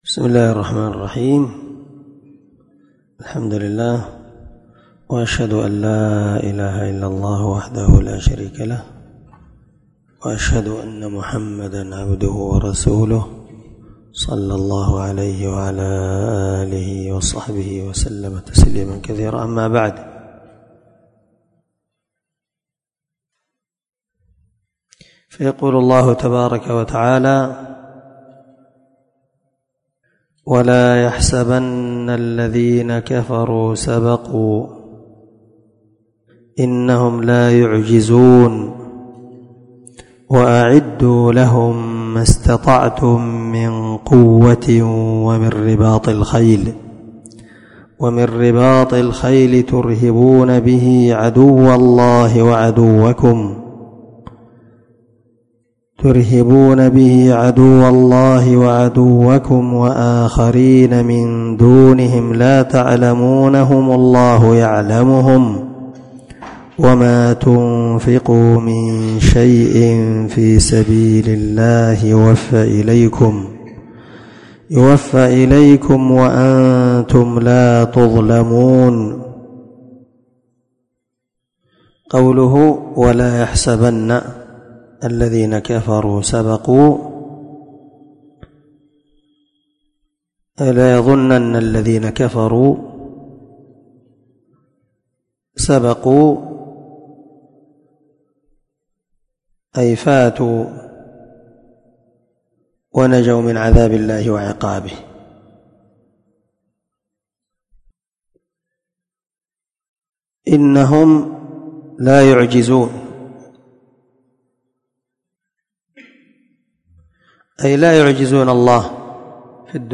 522الدرس19 تفسير آية (59_ 60) من سورة الأنفال من تفسير القران الكريم مع قراءة لتفسير السعدي
دار الحديث- المَحاوِلة- الصبيحة.